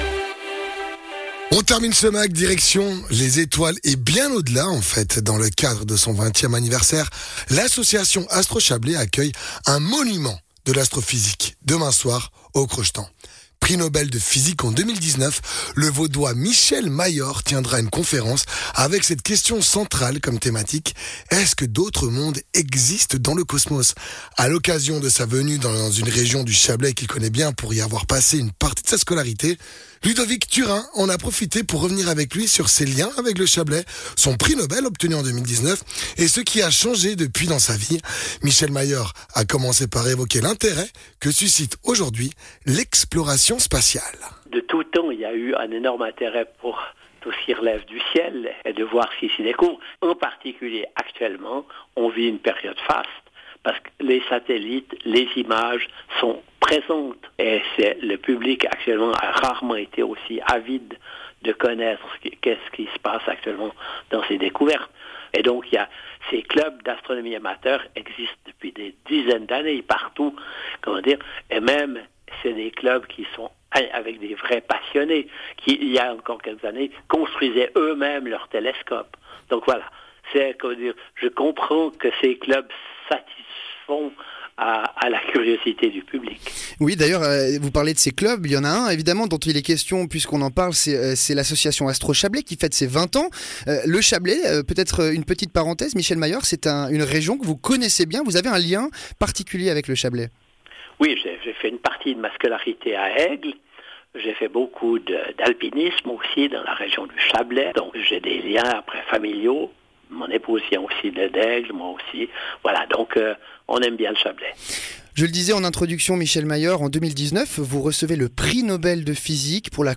Entretien avec le Chablaisien Michel Mayor, prix Nobel de physique en 2019
Intervenant(e) : Michel Mayor, prix Nobel de physique